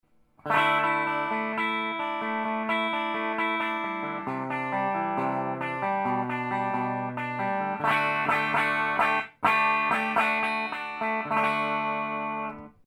音のほうは、素晴らしくジャキジャキ感がタマラナイ感じです！
試しにクリーントーンで弾いてみました。